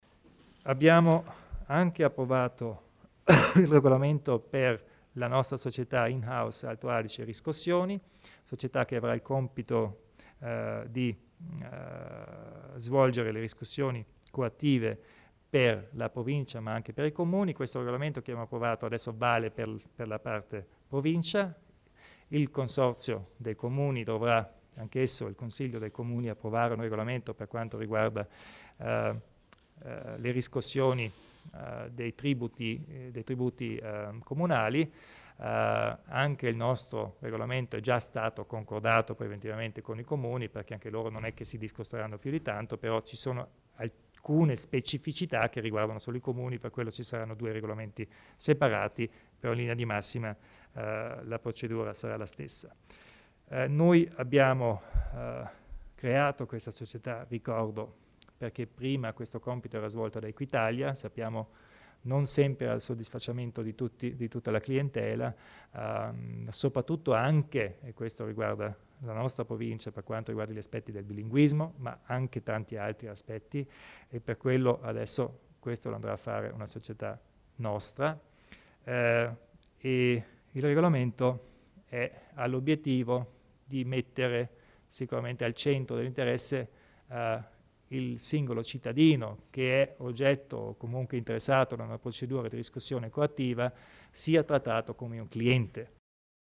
Il Presidente Kompatscher illustra le novità in tema di riscossione tributaria